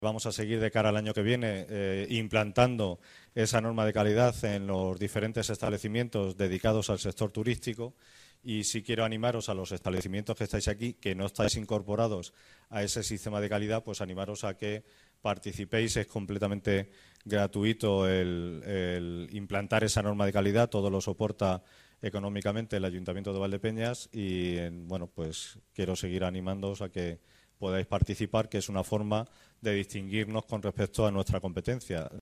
En la entrega de premios, que ha tenido lugar en el salón de plenos de la Casa Consistorial, el Teniente de alcalde de Cultura, Turismo, Educación y Festejos, Manuel López Rodríguez, ha agradecido a los participantes que “sigáis creyendo en la Feria de la Tapa y que sigáis trabajando para poner el nombre de la gastronomía y hostelería de Valdepeñas en el lugar que se merece, por lo que os animo a seguir trabajando en este sentido junto a la administración”.